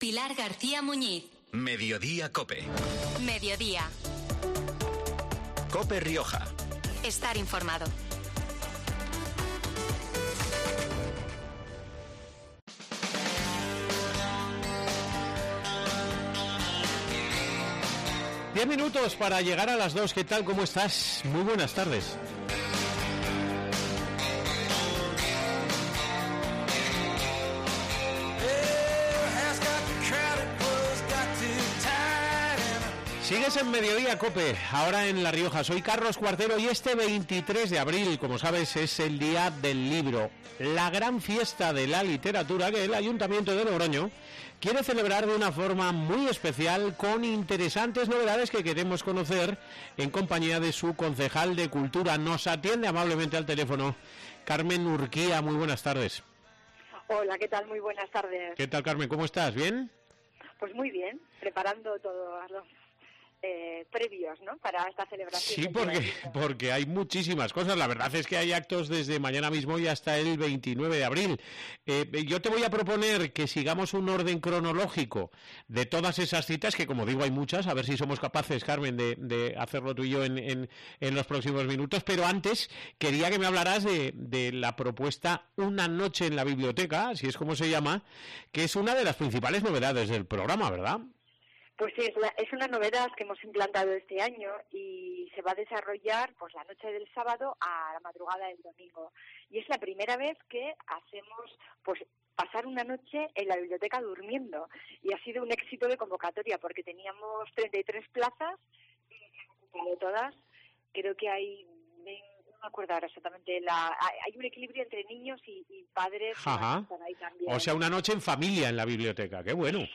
Una lectura pública de poemas, un concierto, una obra de teatro, una tarde de juegos, talleres de encuadernación y la propuesta de 'Una noche en la biblioteca', son algunas de las sugerentes iniciativas que se llevarán a cabo desde el día 21 hasta el 29 de este mes, tal y como ha explicado en COPE Rioja Carmen Urquía, concejal de Cultura.